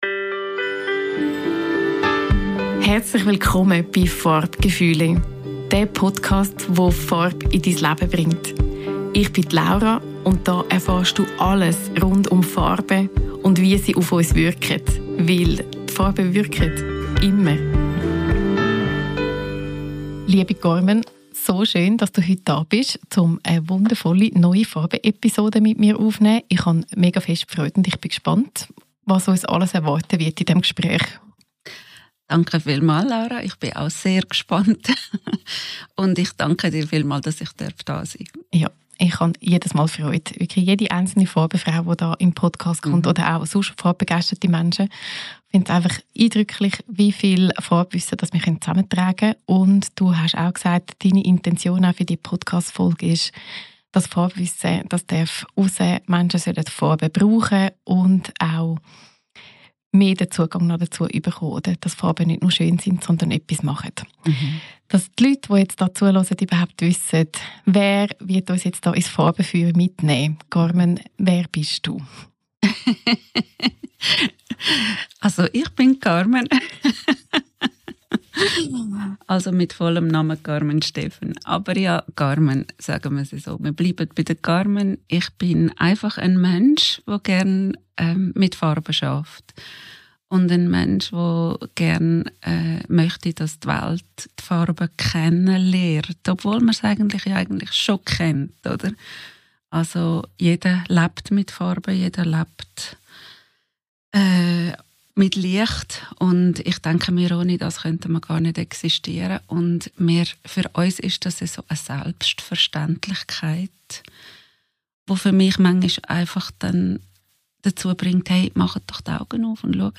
#20 Interview mit Farbtherapeutin